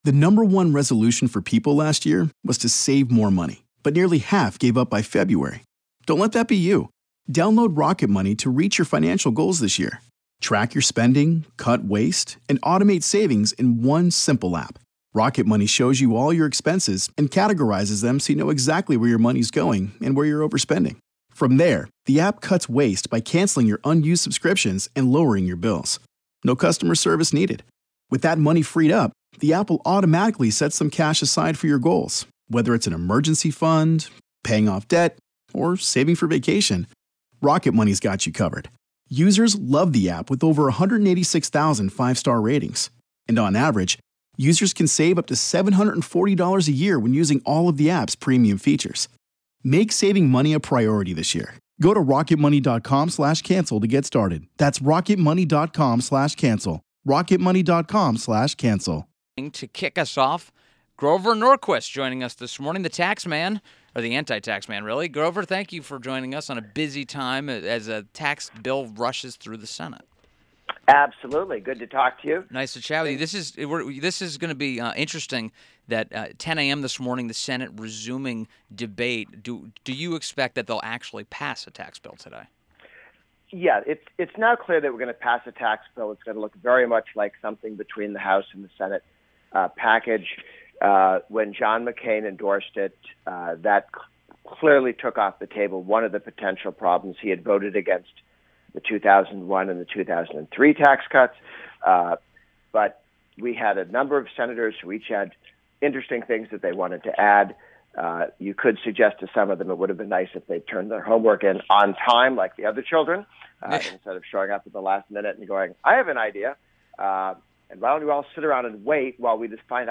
WMAL Interview - GROVER NORQUIST - 12.01.17